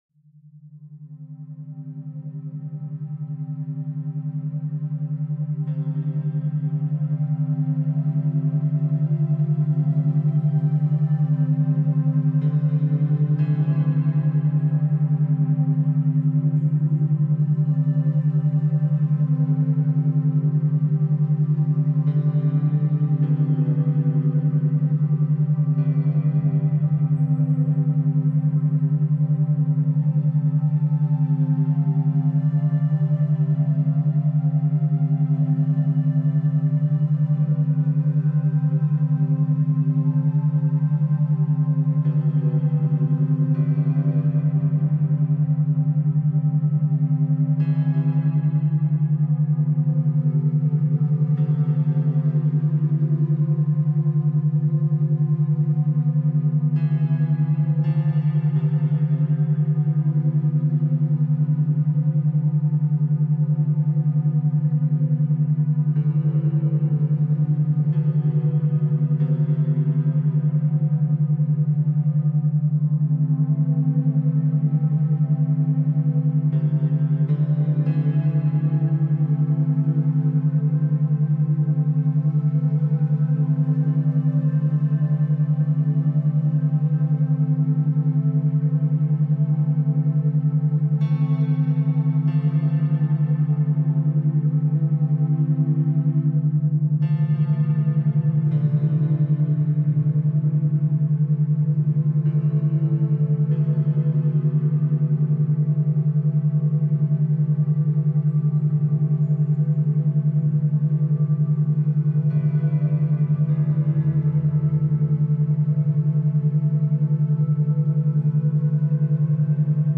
Café d’étude paisible · méthode essentielle 2 heures ressentie